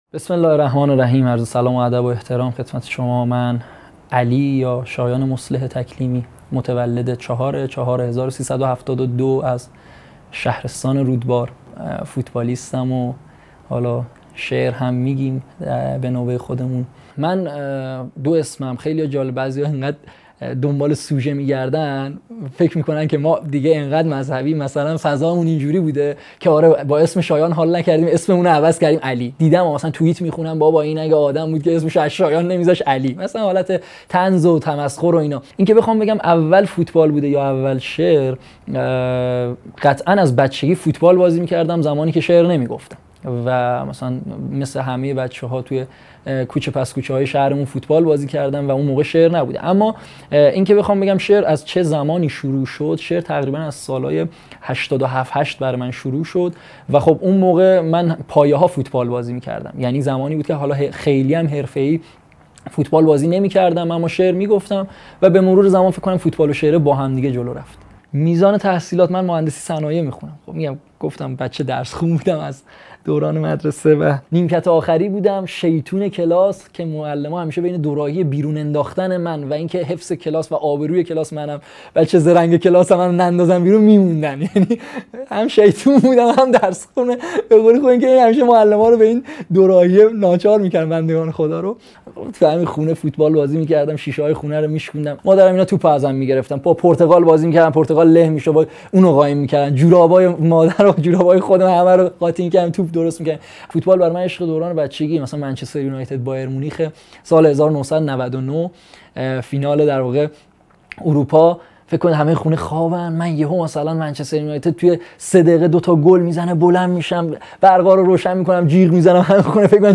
مصاحبه ای خاص